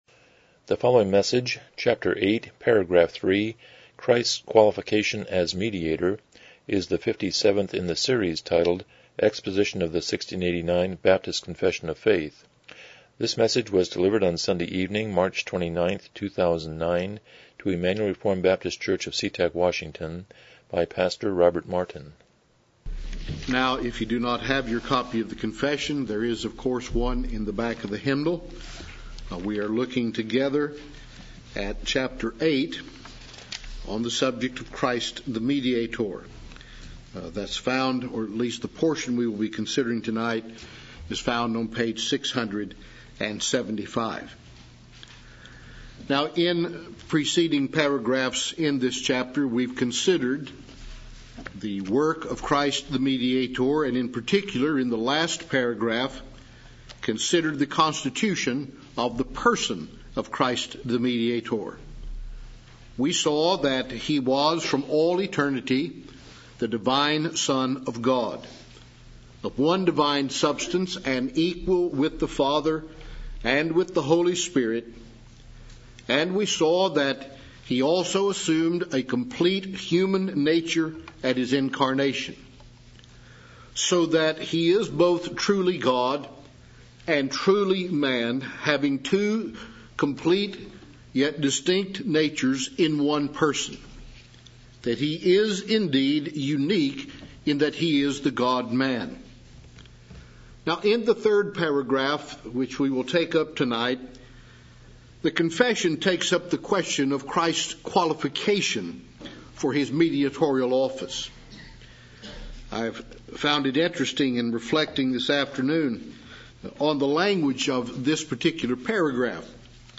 1689 Confession of Faith Service Type: Evening Worship « 76 Romans 6:11-13 27 Noah Blessing His Sons